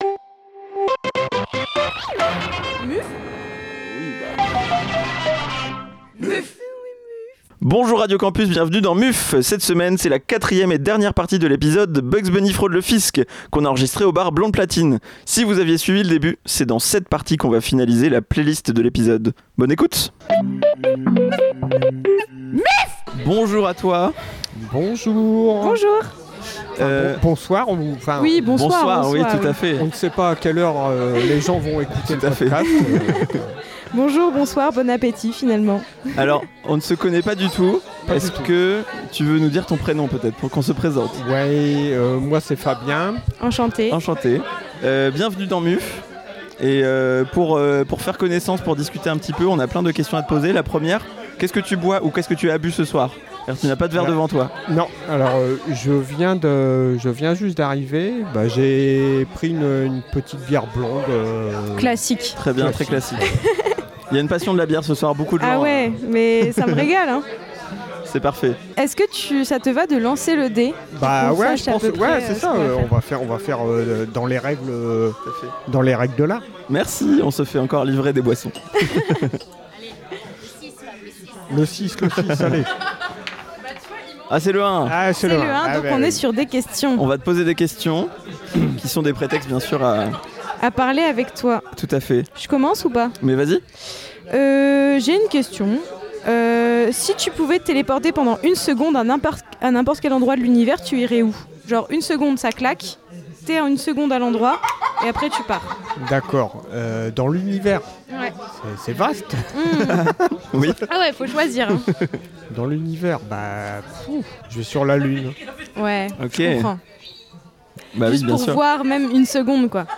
Quatrième et dernière partie de notre épisode enregistré à Blonde Platine !